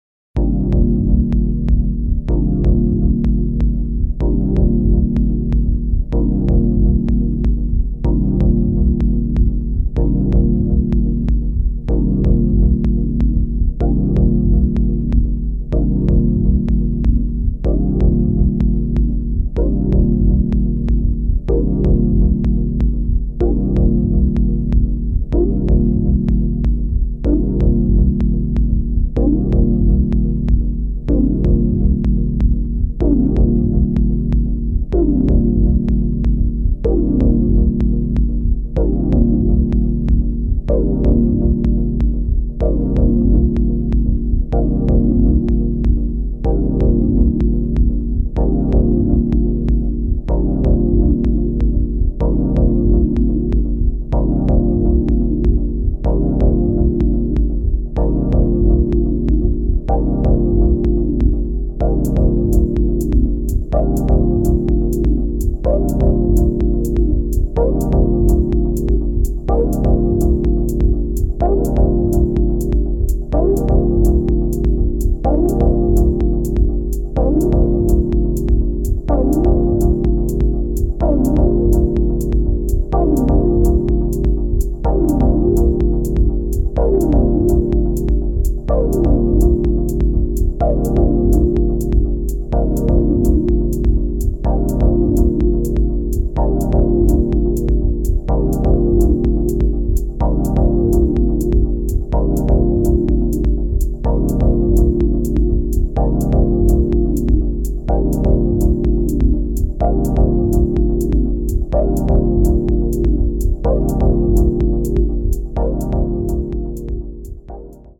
a deep electronic journey